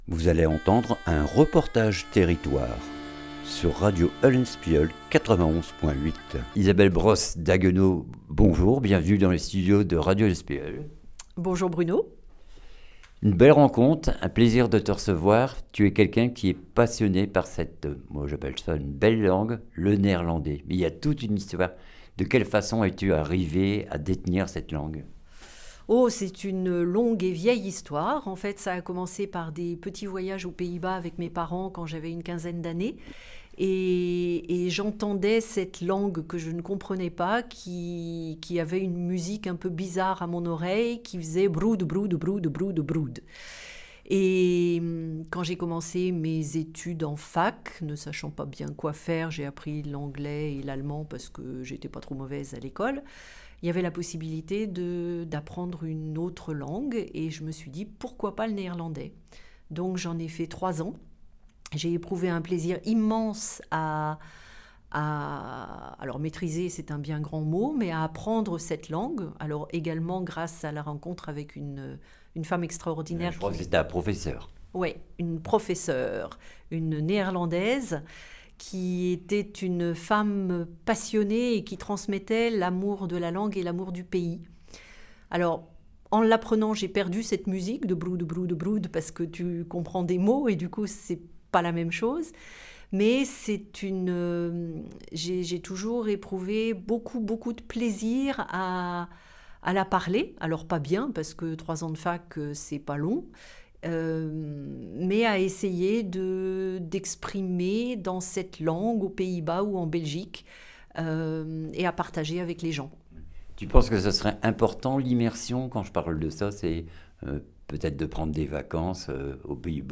REPORTAGE TERRITOIRE PARLEZ - VOUS NEERLANDAIS ?